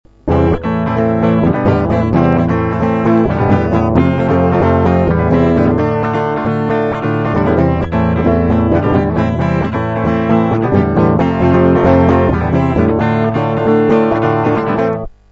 Проигрыш: